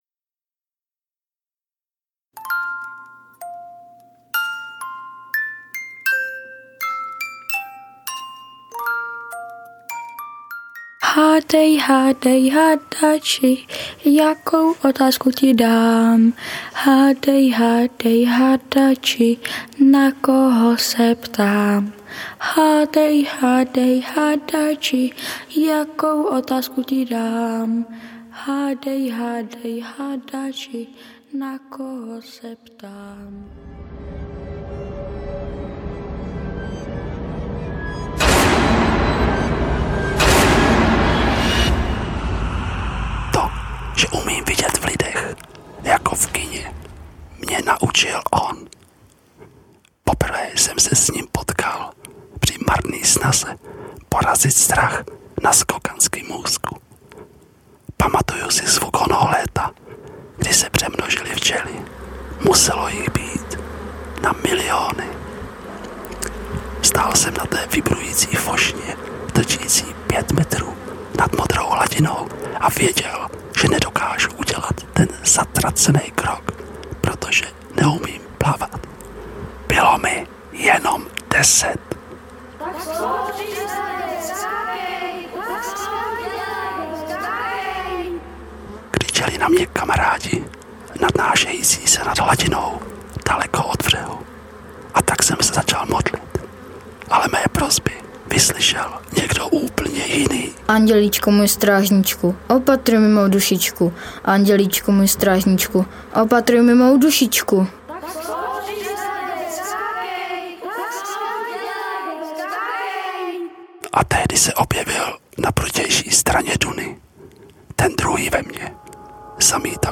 Ukázka z knihy
Celý popis Rok vydání 2019 Audio kniha Ukázka z knihy 69 Kč Koupit Ihned k poslechu – MP3 ke stažení Potřebujete pomoct s výběrem?